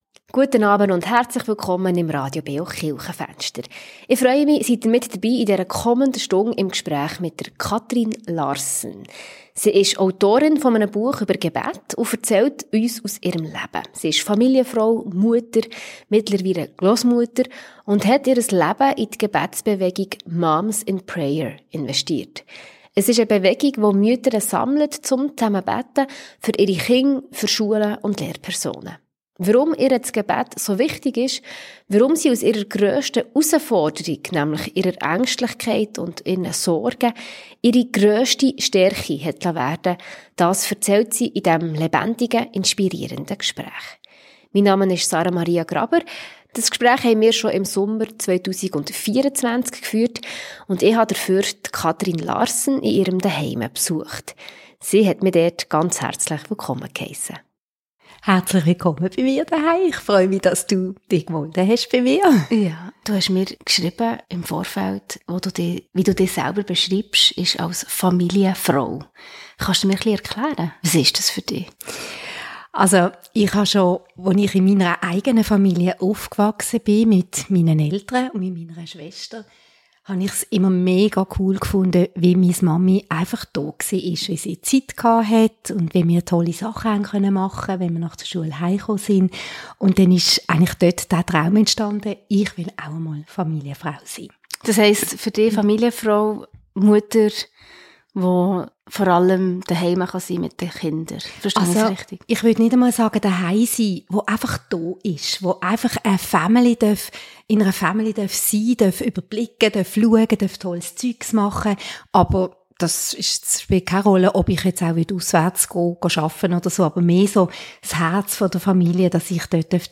Ein ehrliches, lebendiges Gespräch, das zum Beten inspiriert.